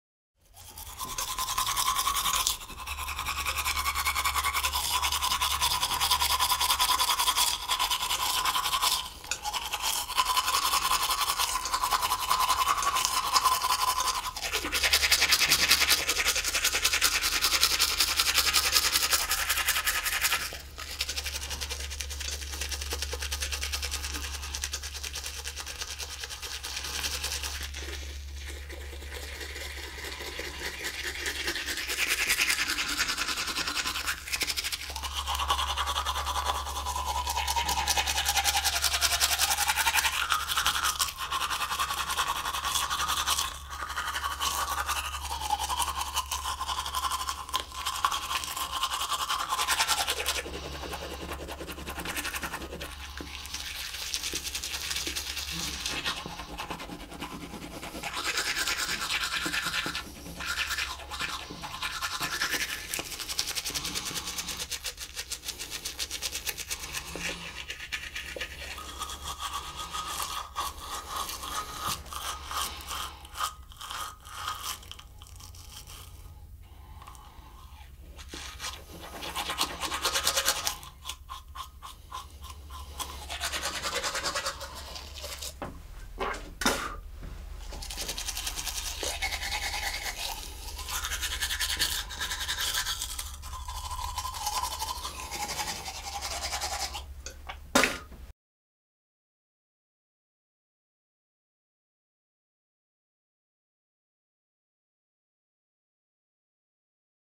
دانلود آهنگ مسواک زدن 2 از افکت صوتی انسان و موجودات زنده
جلوه های صوتی
دانلود صدای مسواک زدن 2 از ساعد نیوز با لینک مستقیم و کیفیت بالا